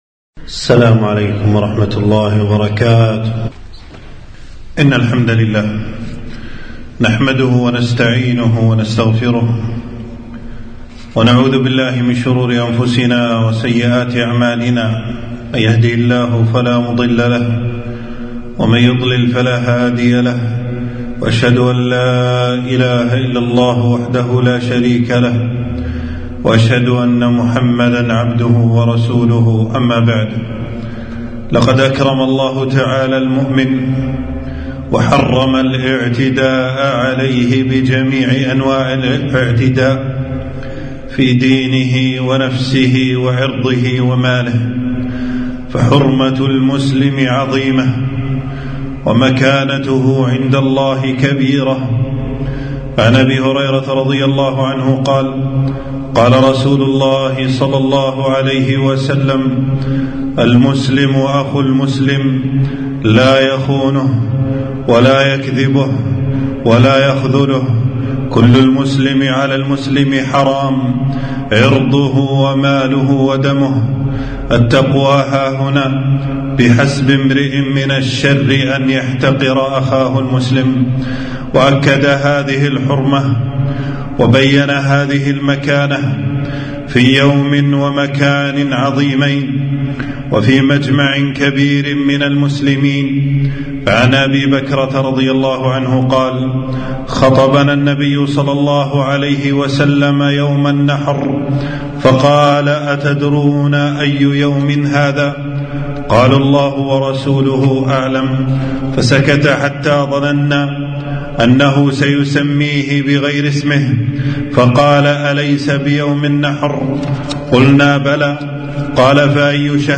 خطبة - سفك الدم الحرام من الموبقات العظام